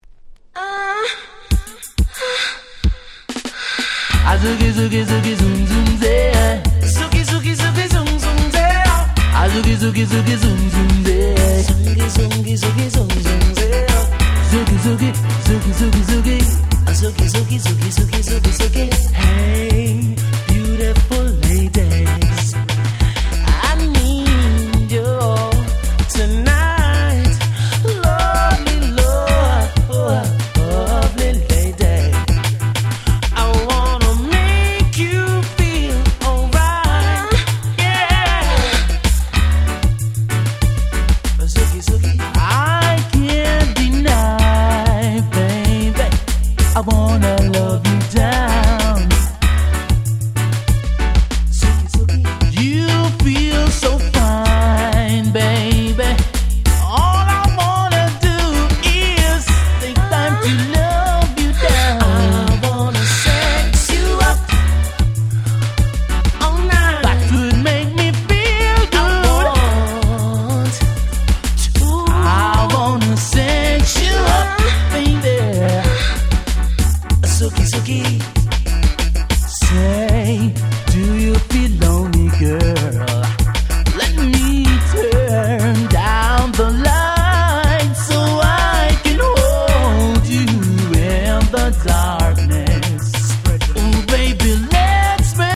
LoversとDancehallの中間的ななかなかにイケてるカバーです！！
カバー ラバーズ ダンスホール